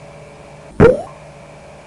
Pop Sound Effect
Download a high-quality pop sound effect.
pop-4.mp3